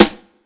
Crispy Snare.wav